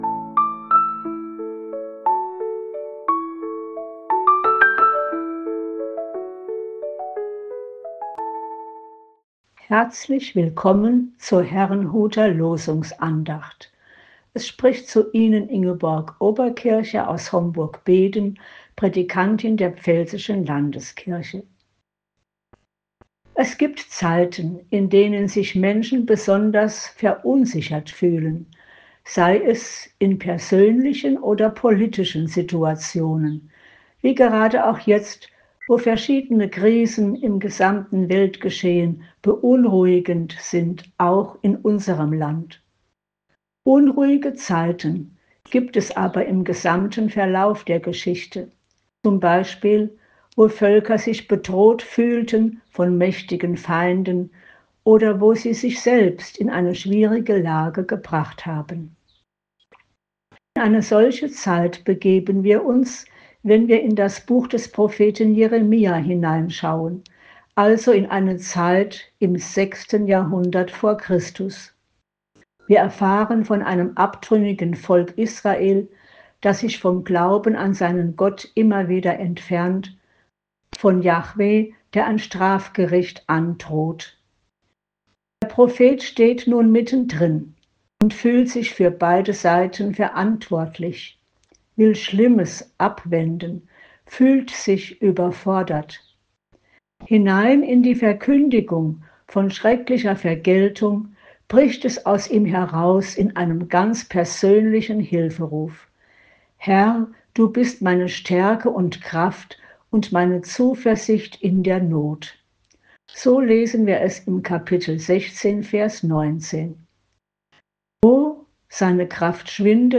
Losungsandacht für Dienstag, 18.03.2025